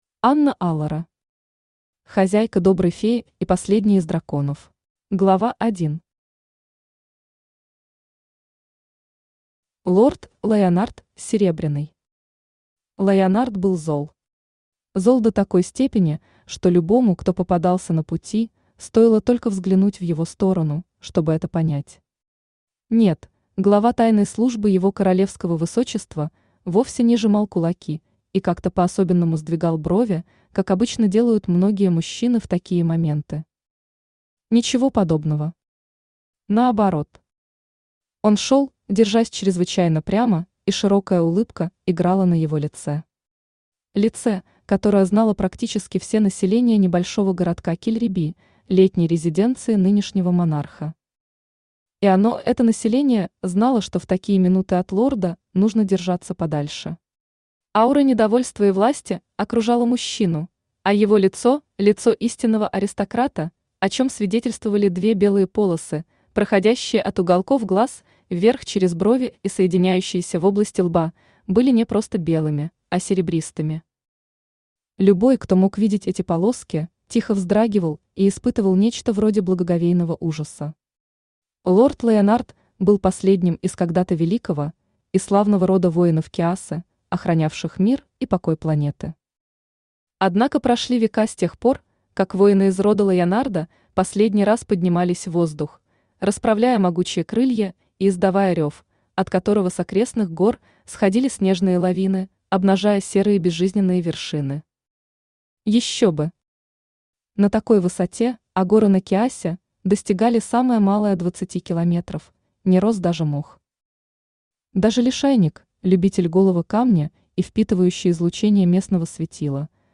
Аудиокнига Хозяйка «Доброй феи» и последний из драконов | Библиотека аудиокниг
Aудиокнига Хозяйка «Доброй феи» и последний из драконов Автор Анна Алора Читает аудиокнигу Авточтец ЛитРес.